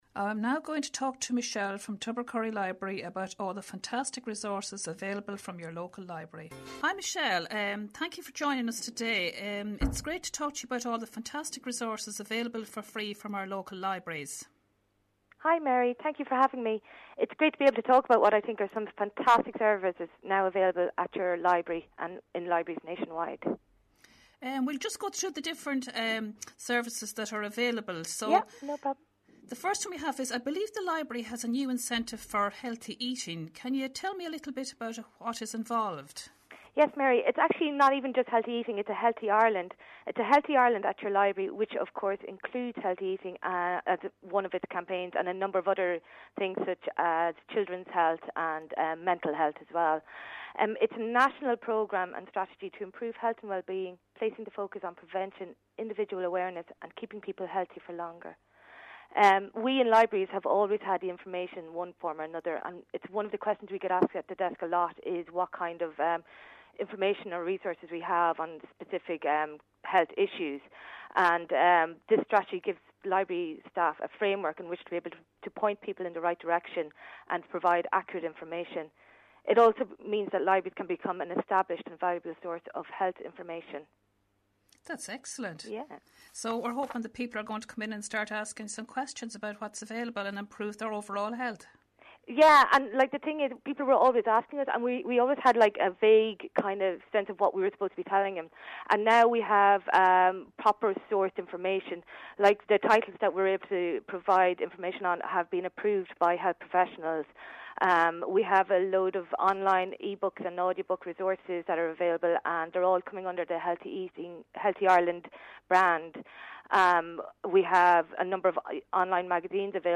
Tubbercurry Library Interview - RosFM 94.6